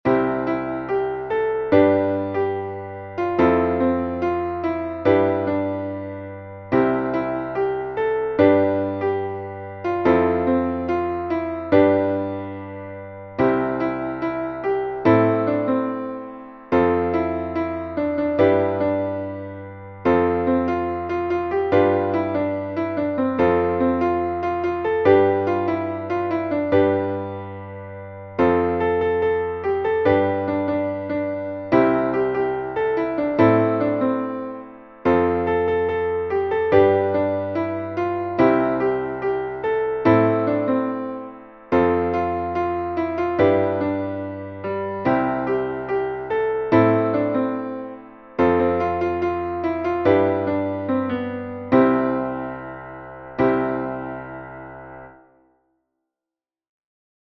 μελωδία και συγχορδίες, Cmaj